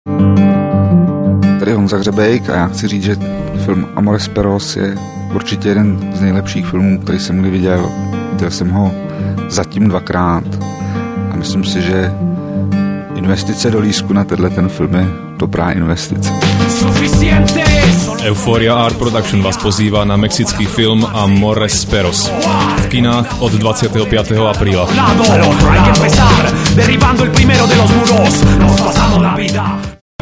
Vypočujte si, čo hovorí o filme český režisér Ján Hřebějk (Pelíšky, Musíme si pomáhať) mp3 (30 sec – 200 Kbytes)